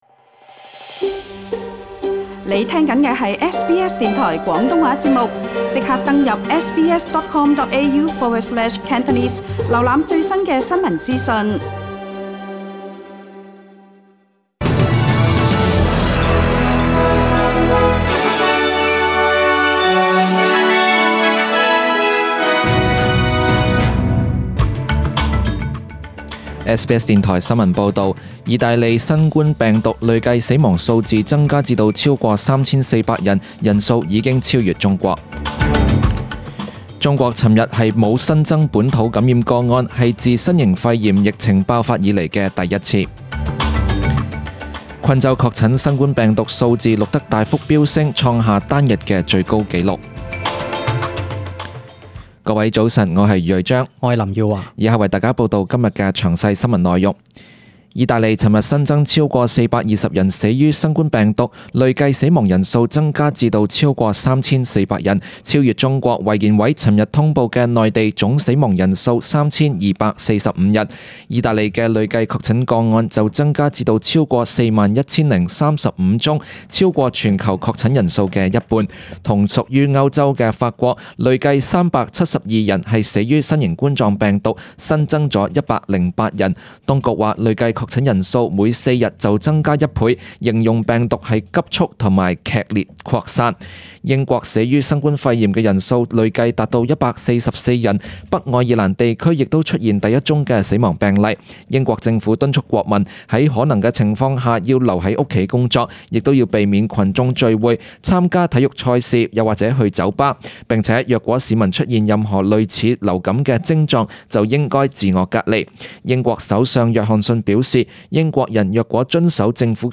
请收听本台为大家准备的详尽早晨新闻
SBS 廣東話節目中文新聞 Source: SBS Cantonese